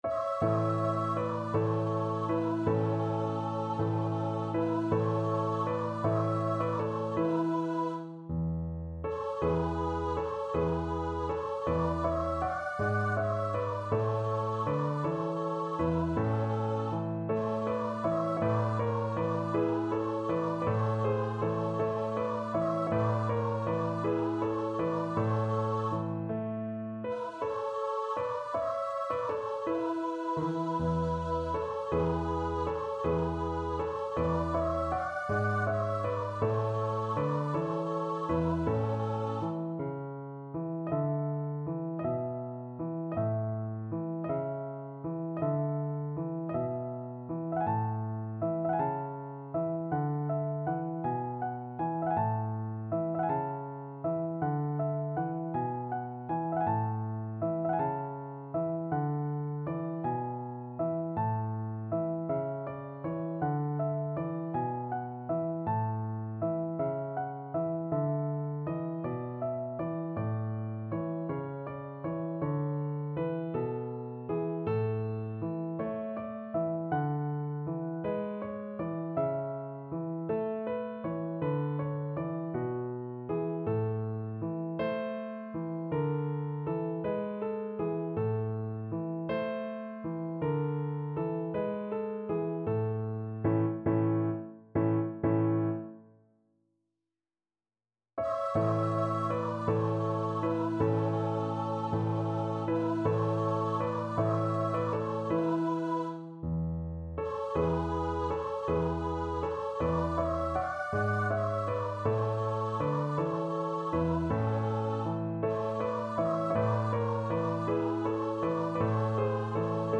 Voice
Sicilian carol
A major (Sounding Pitch) (View more A major Music for Voice )
6/8 (View more 6/8 Music)
~ = 80 Allegro moderato (View more music marked Allegro)
Traditional (View more Traditional Voice Music)